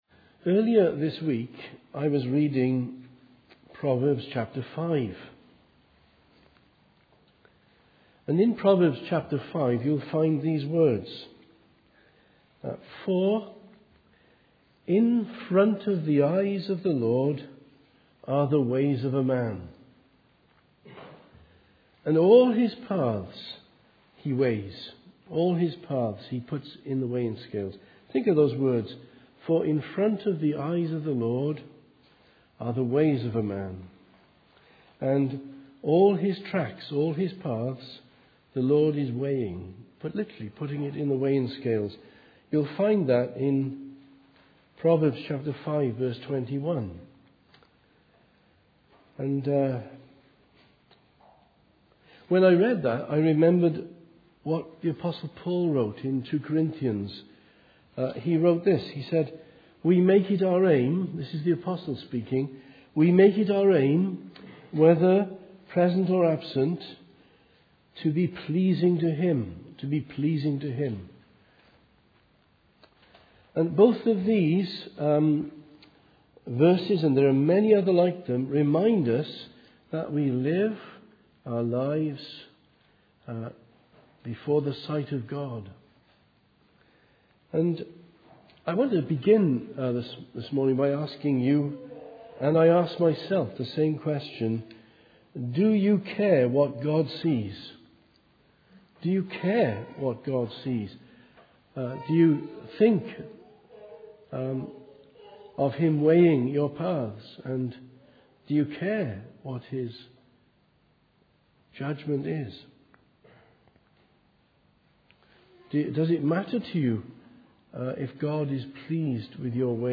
Sermons - Immanuel Presbyterian Church